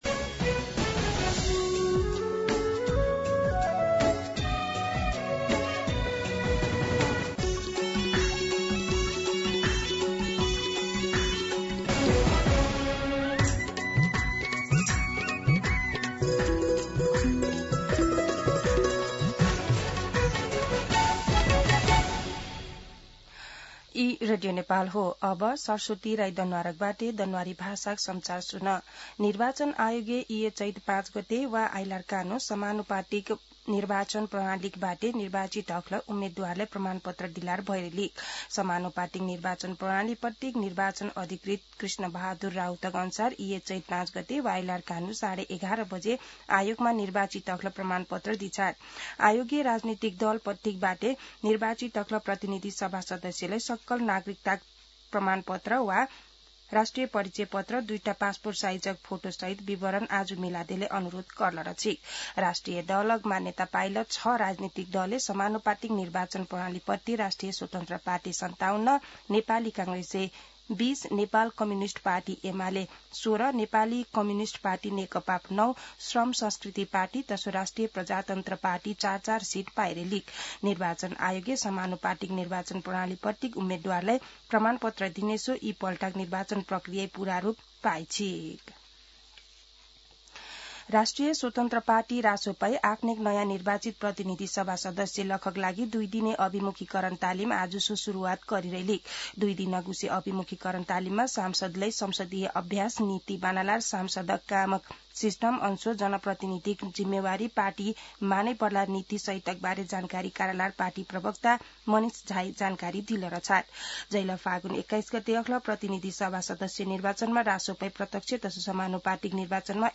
दनुवार भाषामा समाचार : ३ चैत , २०८२
danuwar-News-03.mp3